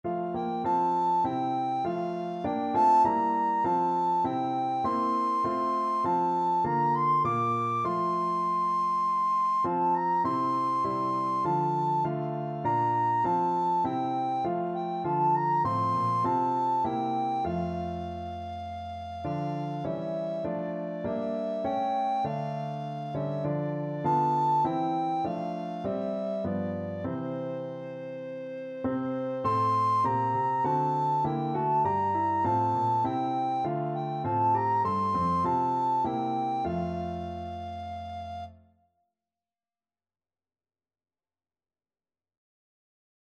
Soprano (Descant) Recorder version
Christmas Christmas Soprano
4/4 (View more 4/4 Music)
C6-D7
Recorder  (View more Easy Recorder Music)
Classical (View more Classical Recorder Music)